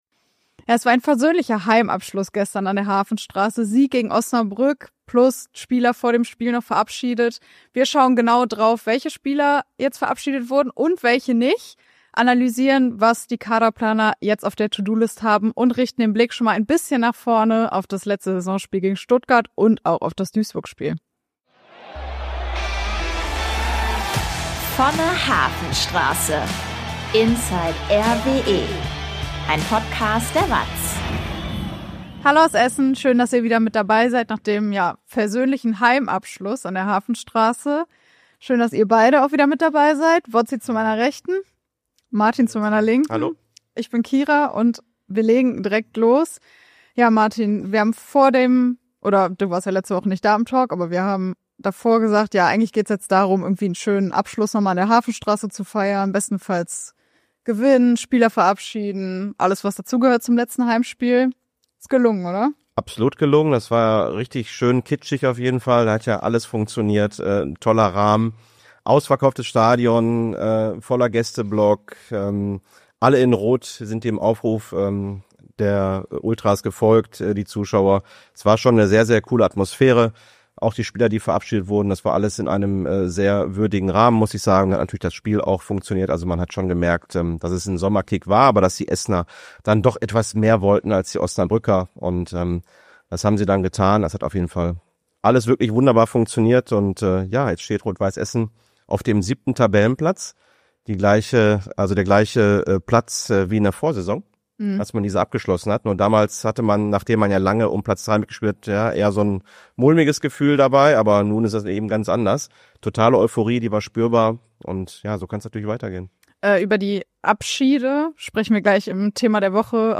Mit diesen Fragen beschäftigen wir uns in der aktuellen Folge unseres RWE-Talks "vonne Hafenstraße."